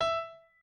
piano9_15.ogg